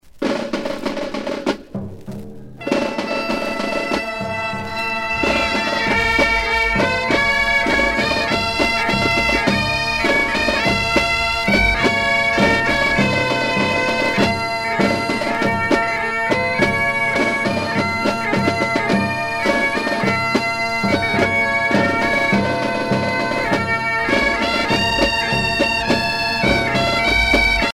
Fonction d'après l'analyste gestuel : à marcher
Pièce musicale éditée